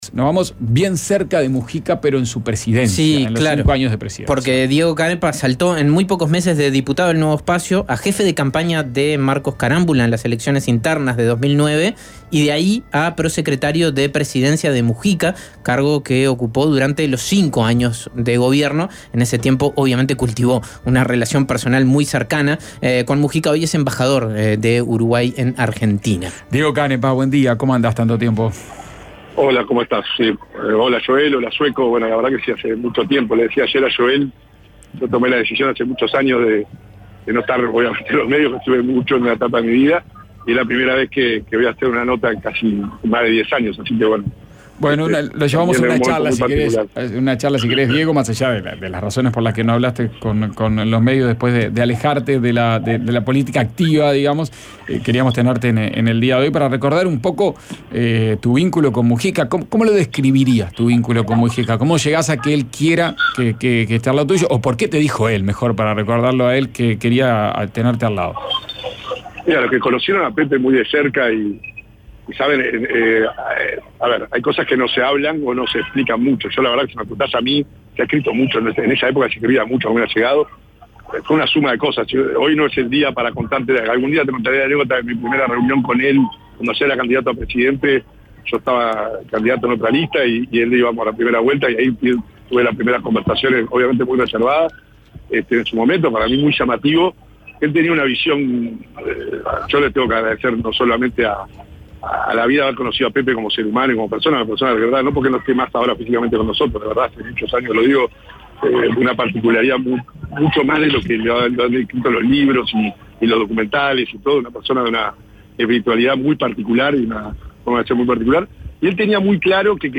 Entrevista a Fernanda Maldonado, directora general de secretaría del MGAP.